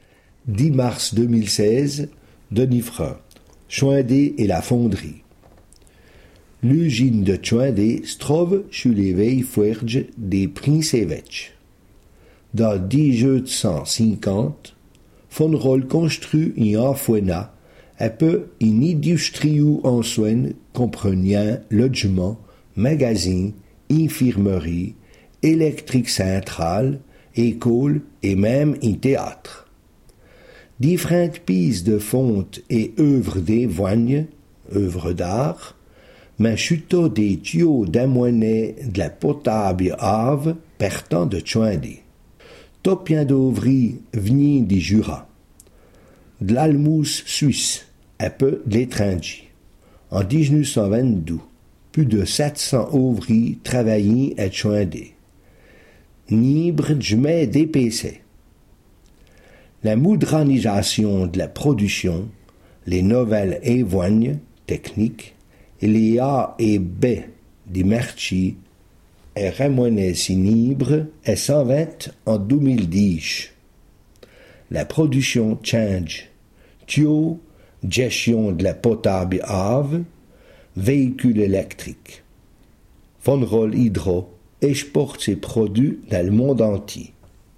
Ecouter le r�sum� en patois, lu par